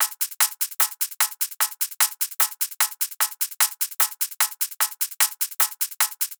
VR_top_loop_hectic_150.wav